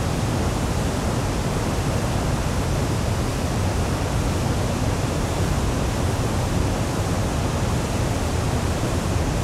waterfall.wav